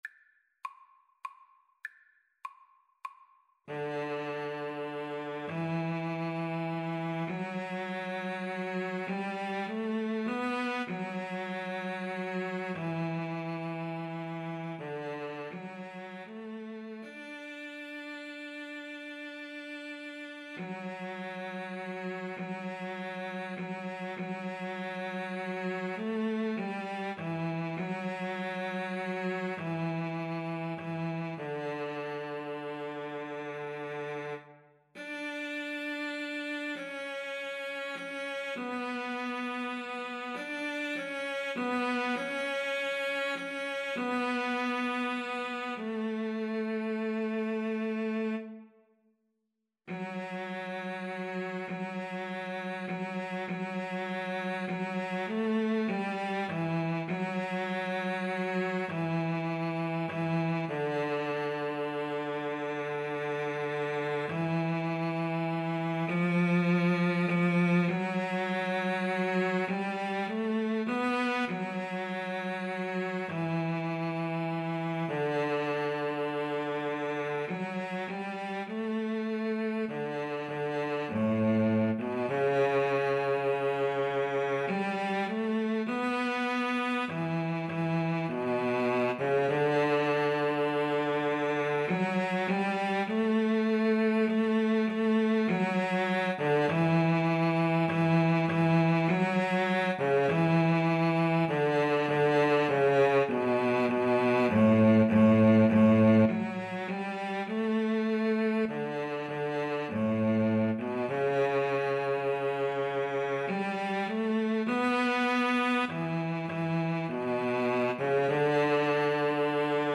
Free Sheet music for Cello Duet
Cello 1Cello 2
3/4 (View more 3/4 Music)
D major (Sounding Pitch) (View more D major Music for Cello Duet )
Moderato
Traditional (View more Traditional Cello Duet Music)